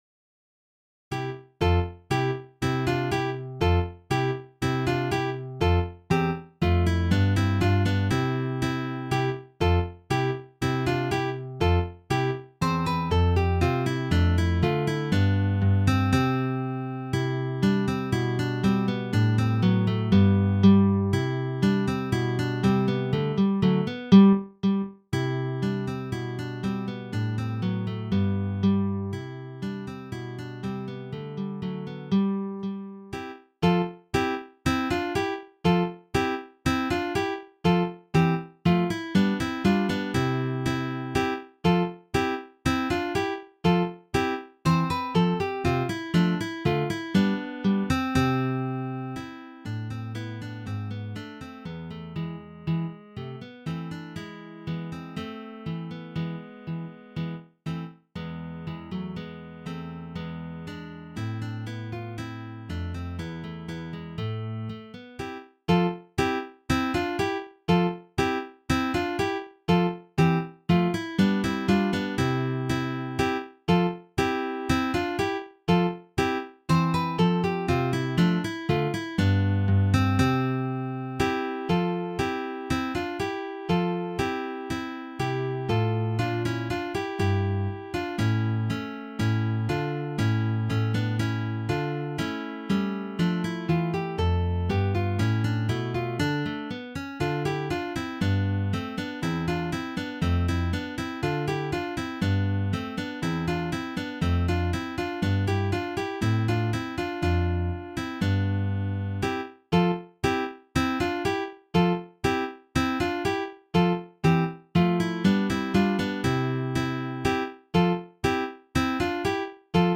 for three guitars
This is from the Baroque period.